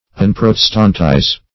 unprotestantize - definition of unprotestantize - synonyms, pronunciation, spelling from Free Dictionary
Unprotestantize \Un*prot"es*tant*ize\, v. t. [1st pref. un- +